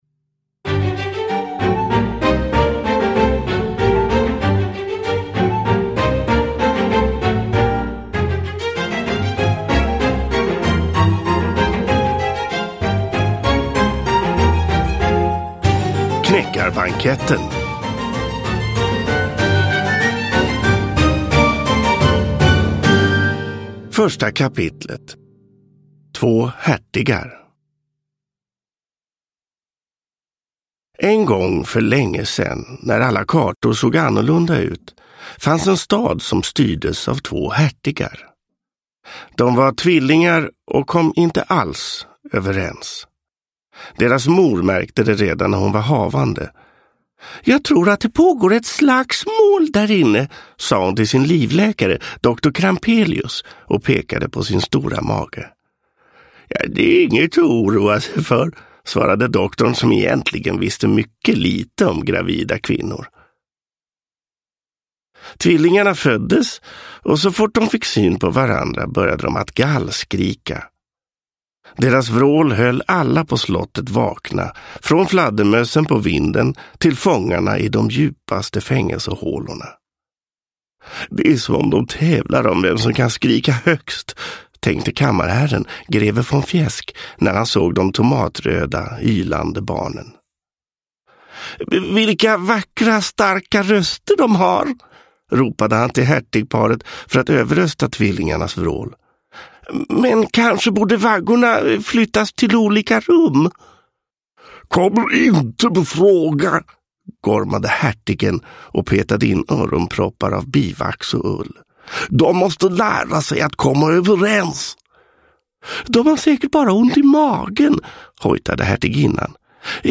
Knäckarbanketten – Ljudbok – Laddas ner